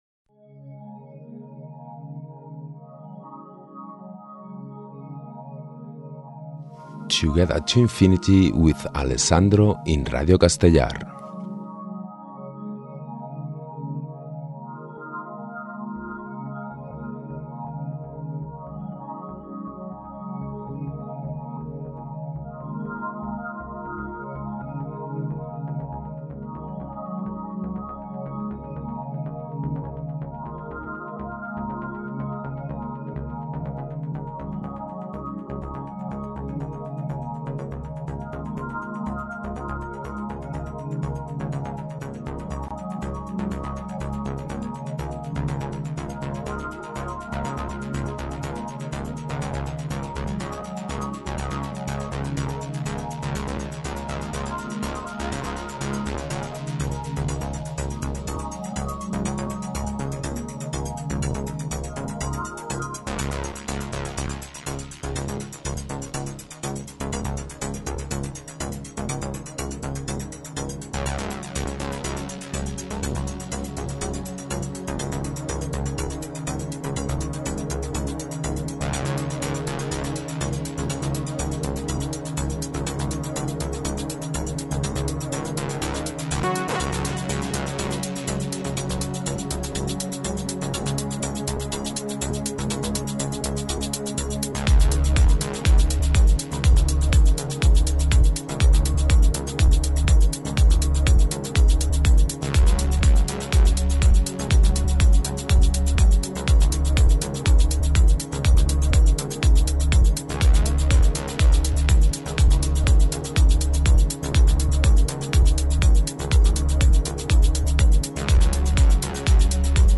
música electrònica amb connexions eivissenques